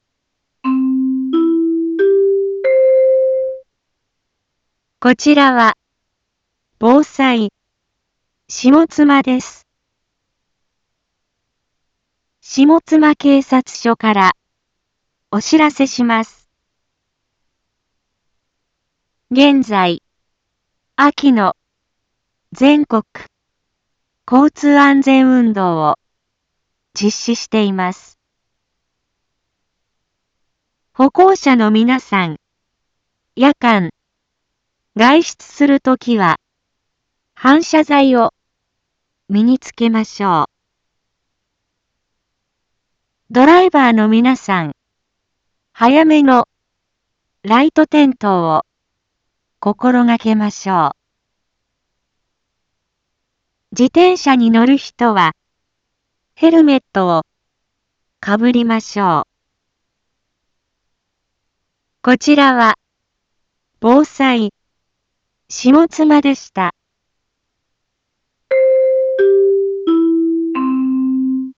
一般放送情報
Back Home 一般放送情報 音声放送 再生 一般放送情報 登録日時：2024-09-27 18:01:16 タイトル：秋の全国交通安全運動について インフォメーション：こちらは、ぼうさい、しもつまです。